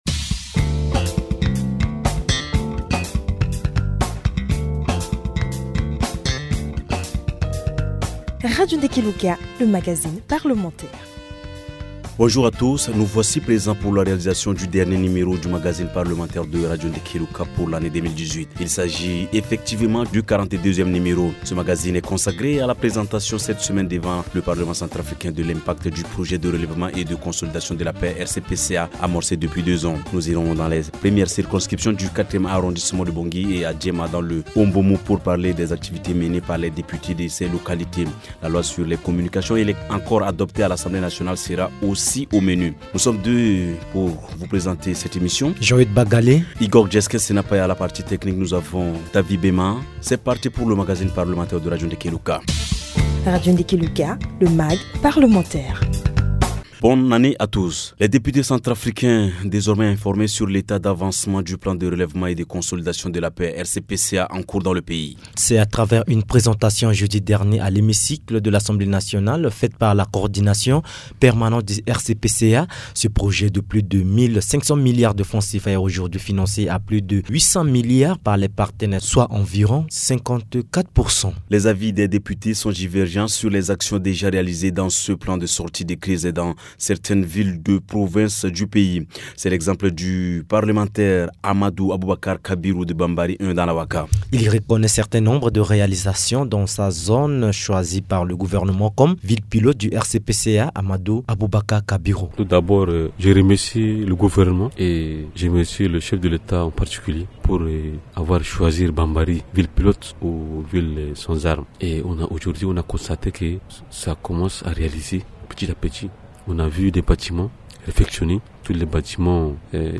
Plus de deux ans après la table ronde de Bruxelles, le plan de consolidation de la paix (RCPCA) déjà financé à plus de 54% est loin de faire l’unanimité à l’Assemblée nationale. Après une présentation par le secrétariat, certains élus sont satisfaits d’autres en sont moins. Ces élus expriment leurs points de vue dans cette émission parlementaire.